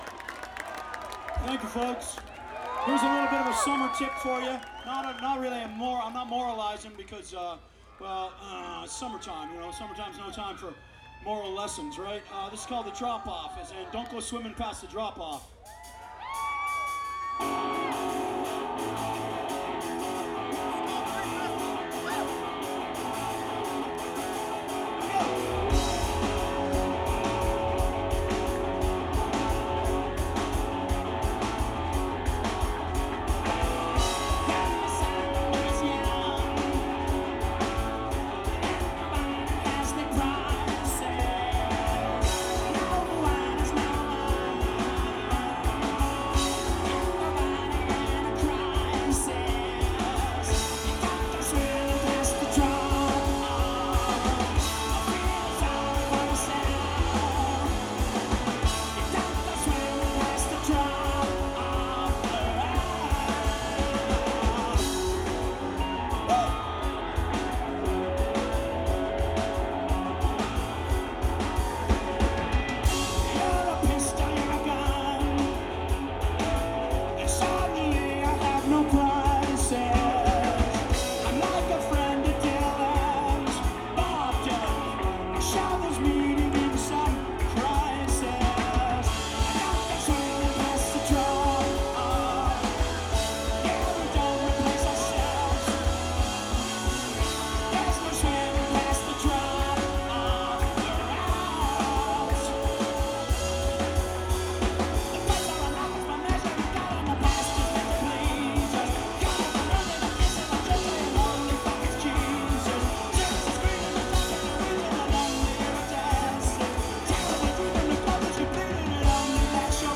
Source: Audience
(3rd time played live)